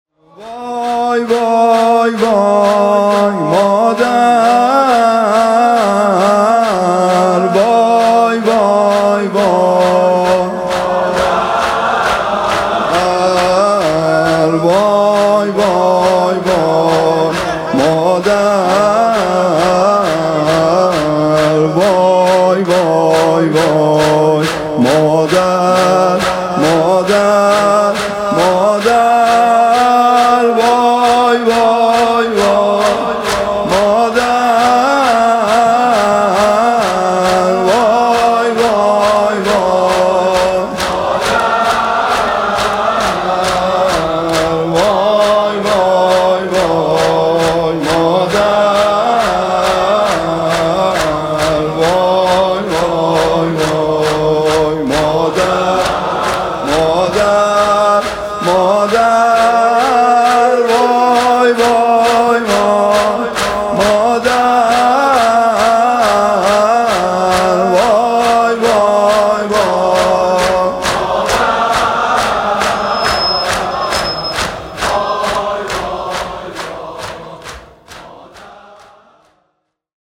[آستان مقدس امامزاده قاضي الصابر (ع)]
عنوان: شب شهادت حضرت زهرا (س)